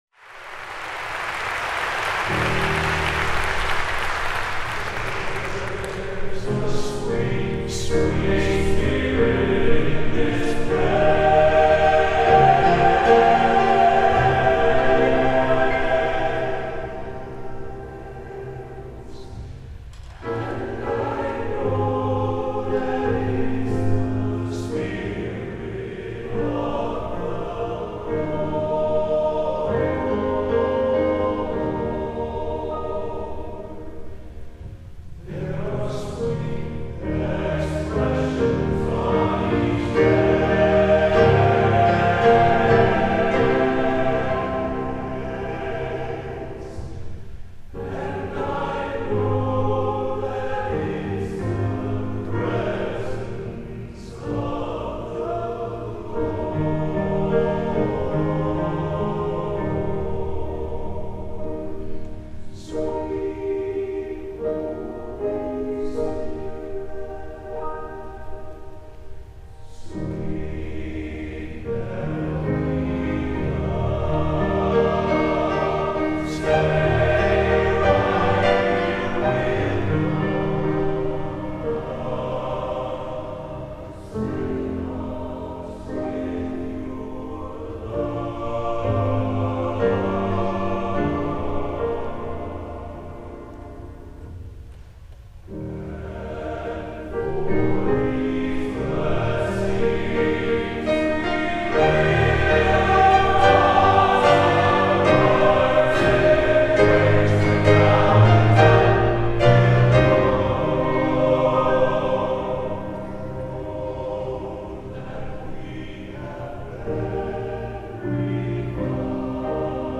Location: West Lafayette, Indiana
Genre: Sacred | Type: